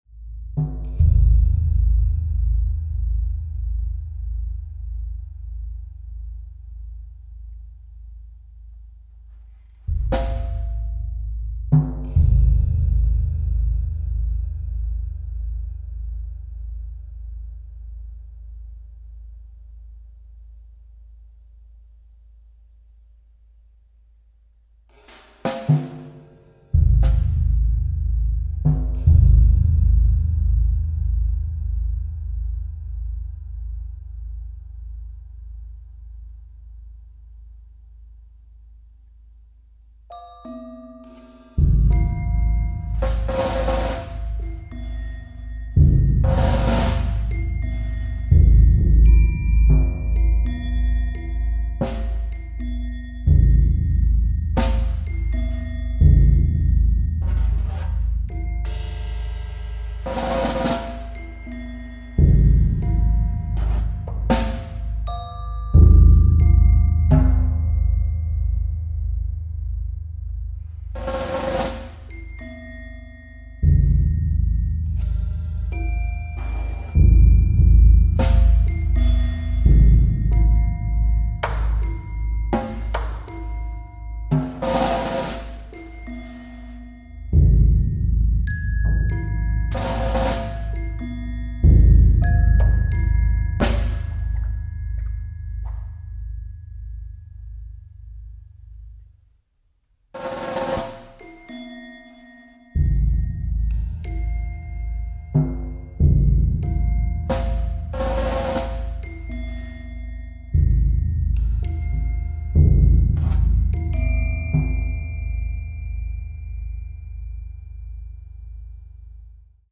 Glasses = Bowed Glas Harmonica